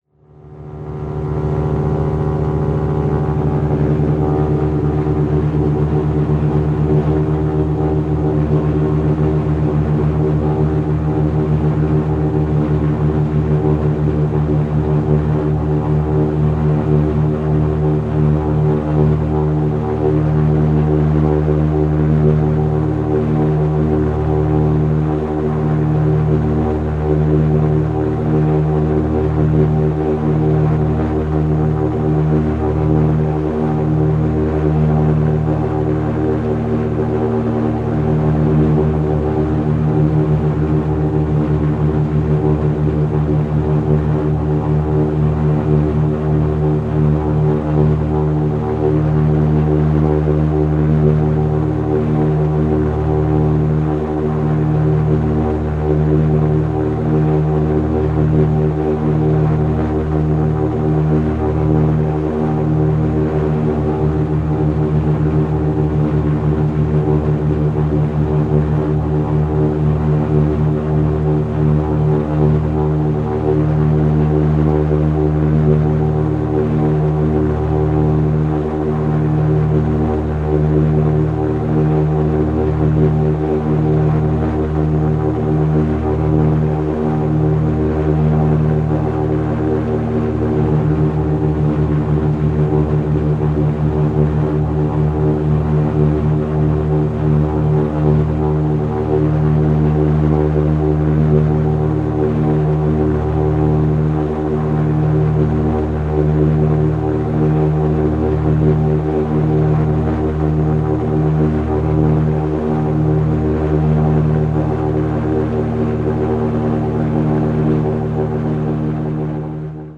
Шум двигателя дирижабля